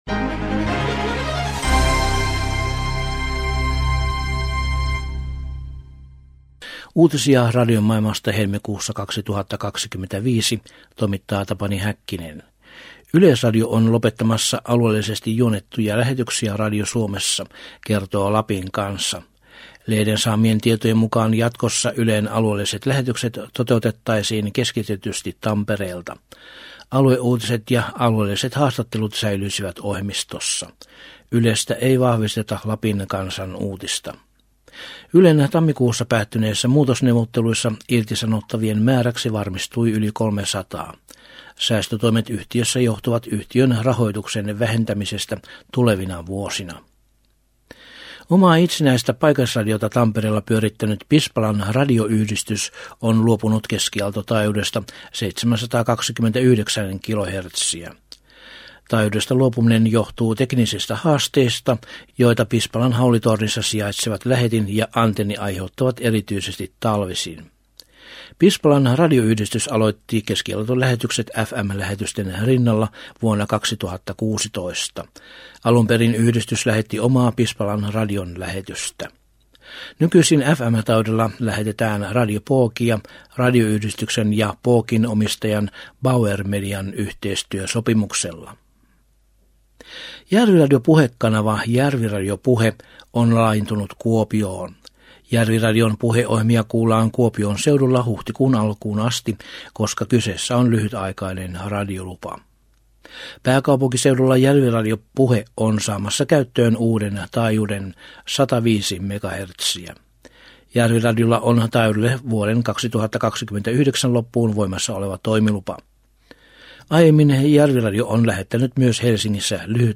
Helmikuun 2025 uutislähetyksessä aiheina ovat muun muassa mahdolliset muutokset Yleisradion aluelähetyksiin Radio Suomessa, Järviradio Puheen laajeneminen Kuopioon tilapäisluvalla ja Britannian yhteisöradiojärjestön tavoite saada uusia lupia AM- ja FM-taajuuksille.